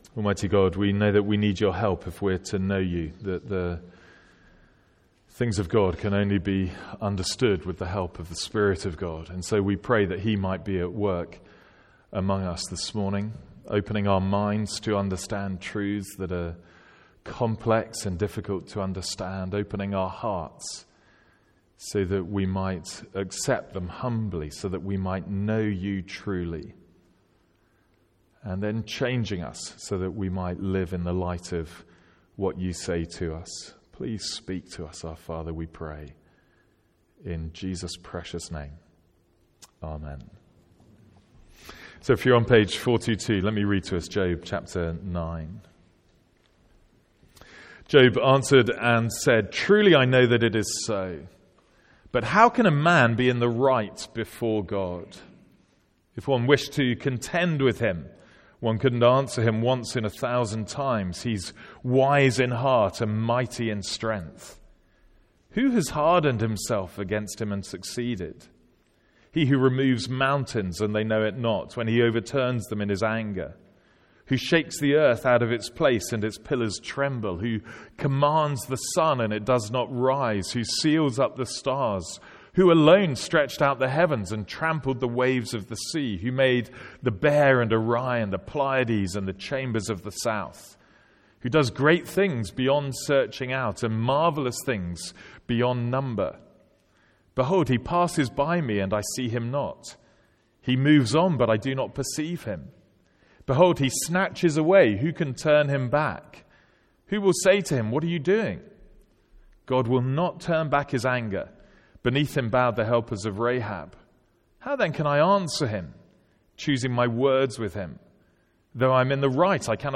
Sermons | St Andrews Free Church
From our morning service in Job.